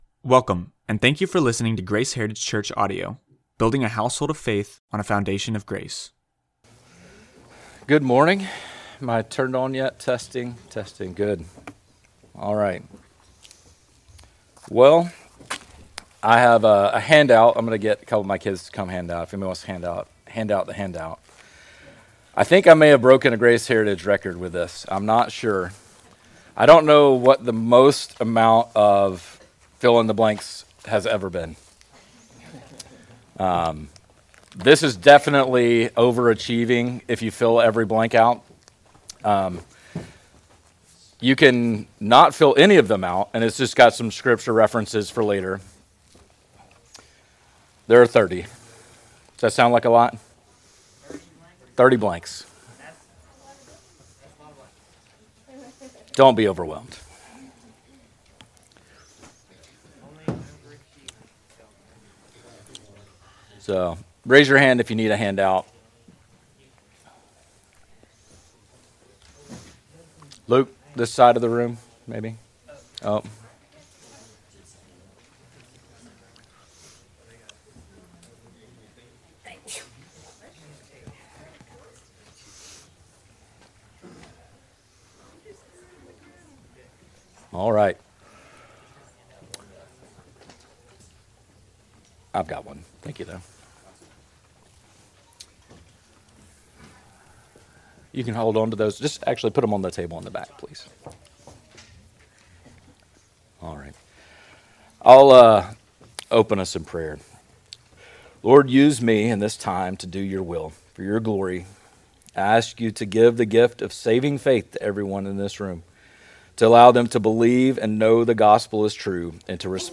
Unfortunately, audio for the second half of this bible study is unavailable.
Tagged with Bible Study